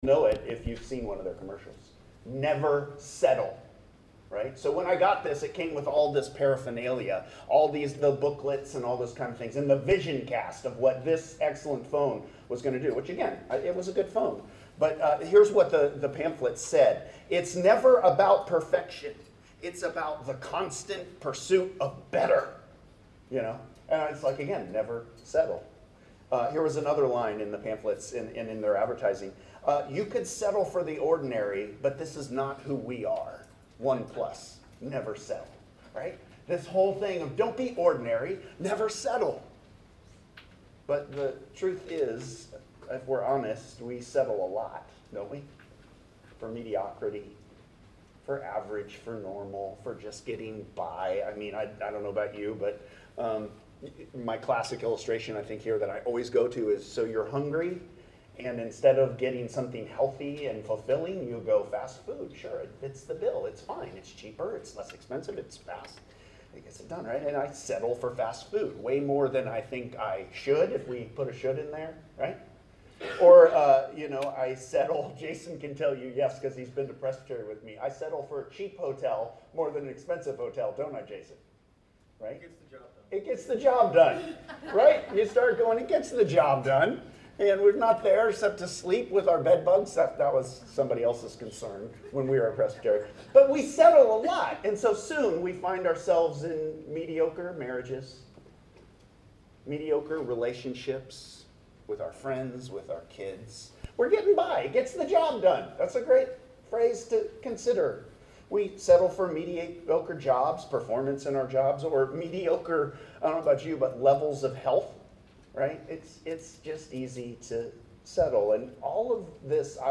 Sermons | Hope Presbyterian Church of Crozet